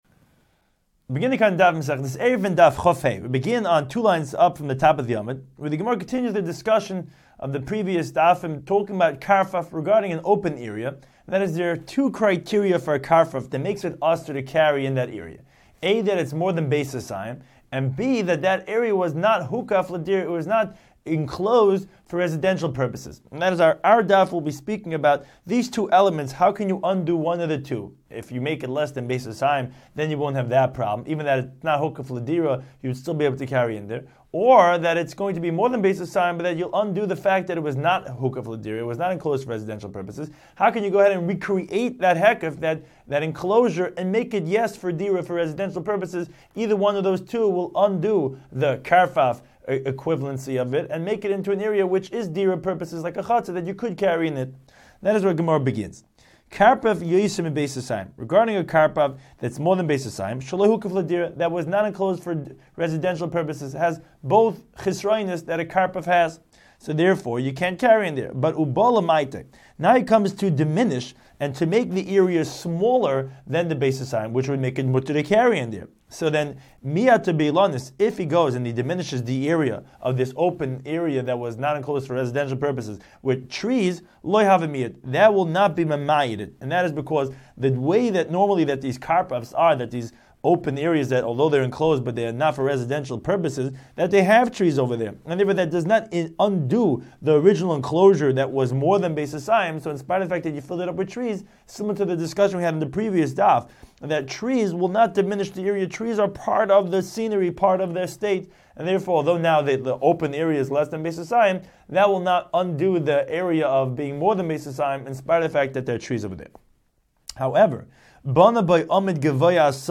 Daf Hachaim Shiur for Eruvin 25